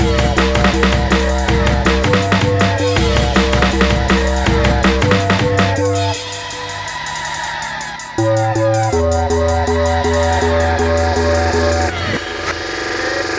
32 Hardcore-b.wav